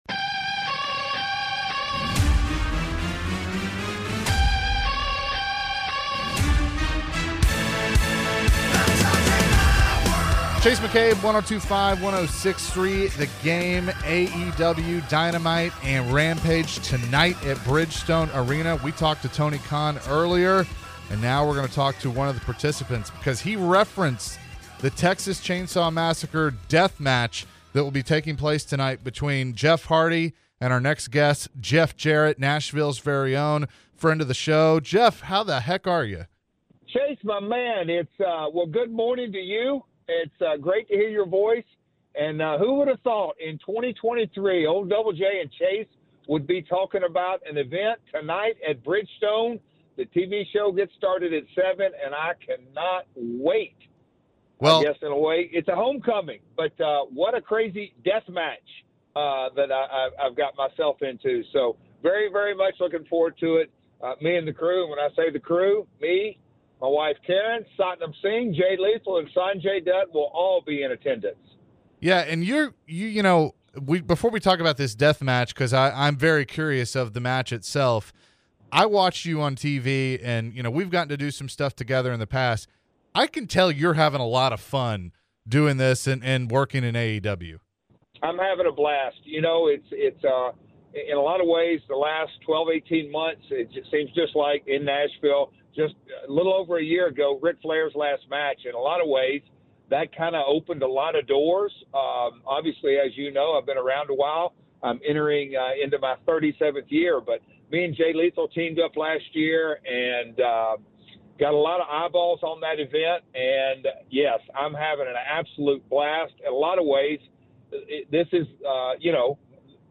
Jeff Jarrett Interview | (8-16-23)